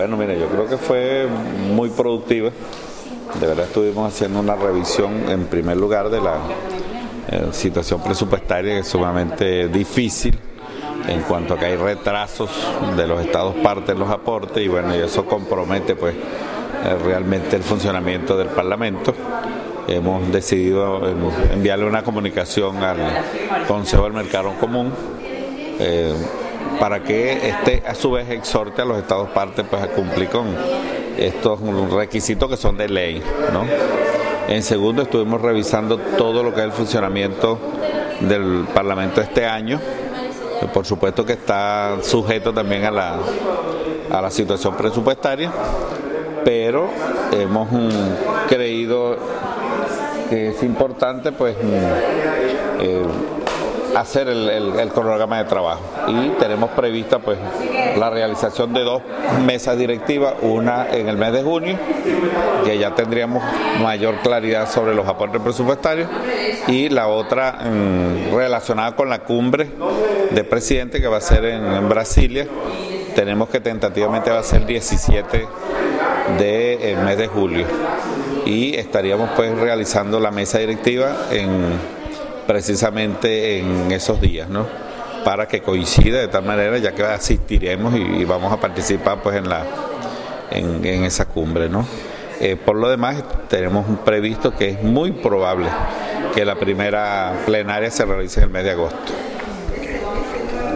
Parlamentario Saúl Ortega, Presidente del PARLASUR